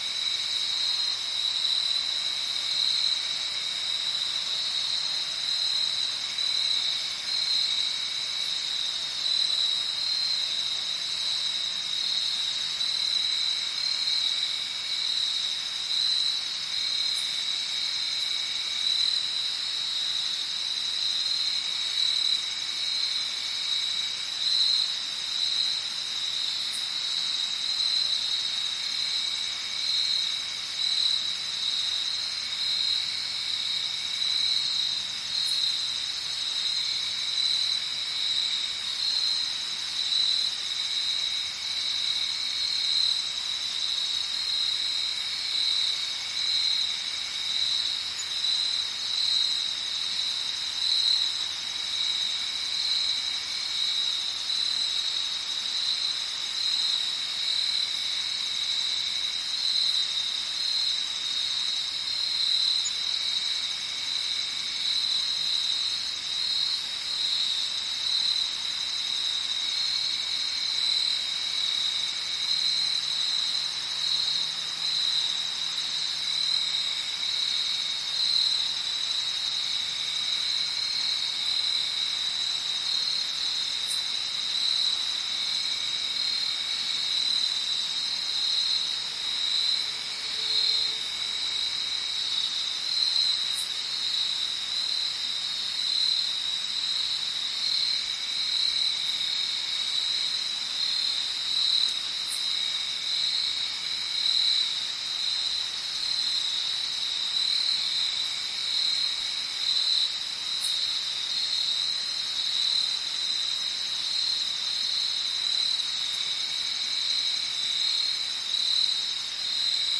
阿丹（清真寺召唤）早上5点，NR
描述：早上5点呼叫RASD（阿拉伯撒哈拉民主共和国）难民营的清真寺"27 de Febrero"。用AT822话筒在迷你光盘上录制立体声。用Soundtrack Pro降噪（高音量时可听到人工痕迹）。
标签： 场记录 撒哈拉 鸟类 打电话 宣礼 早晨 清真寺
声道立体声